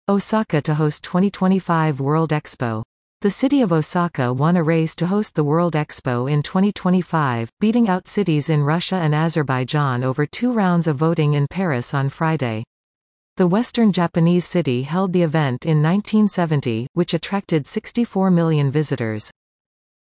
※ここでは標準的なアメリカ英語のリスニングを想定しています。